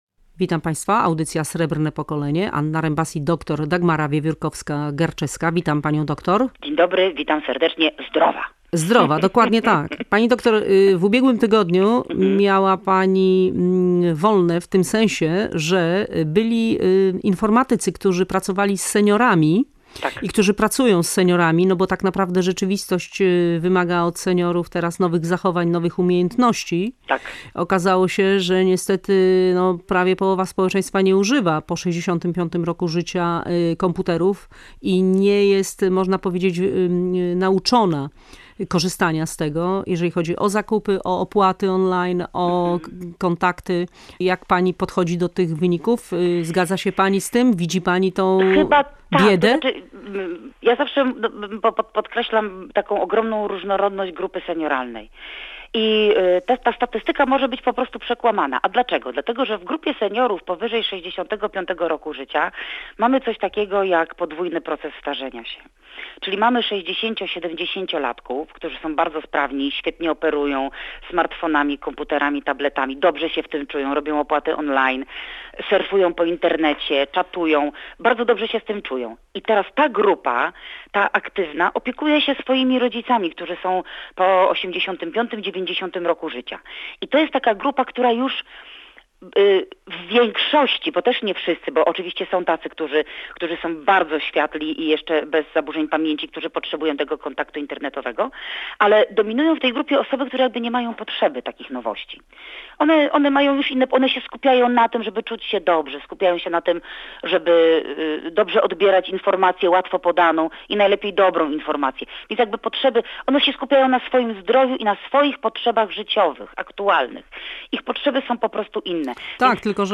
geriatra internista. Podzieliła się ze słuchaczami swoimi spostrzeżeniami na temat luzowania przepisów.